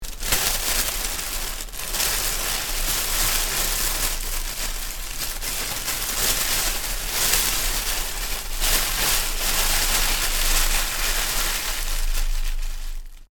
关于急忙翻书声音效的PPT演示合集_风云办公